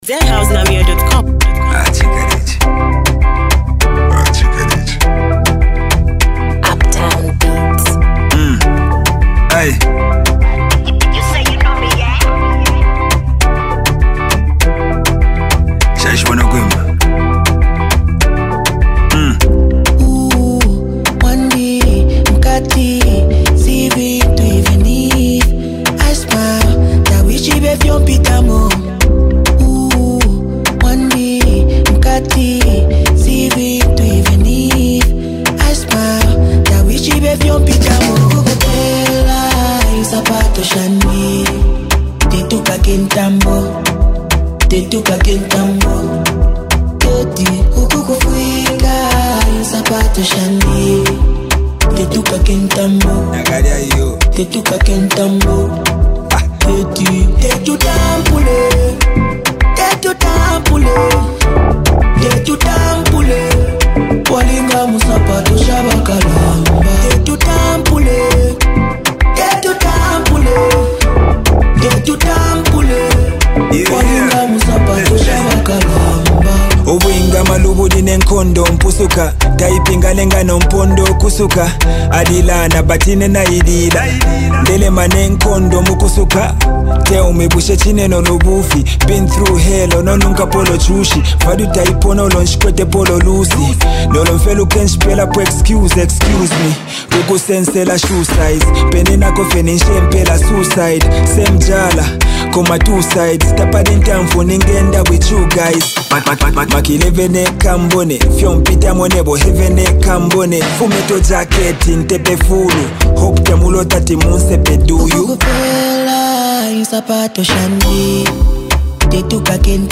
melodic hooks
sharp bars